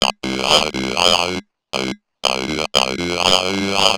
BASS LOOPS - PAGE 1 2 4 5